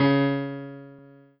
piano-ff-29.wav